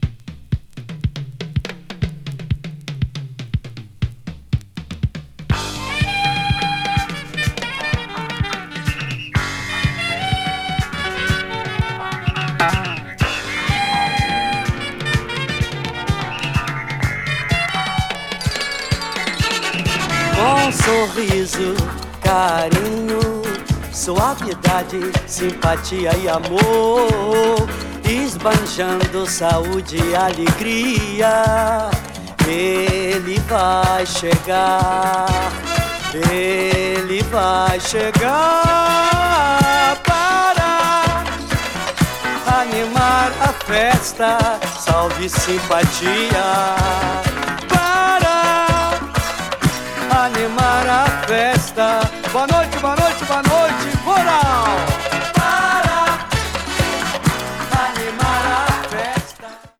B-5)など、ブラジリアン・ディスコが最高です！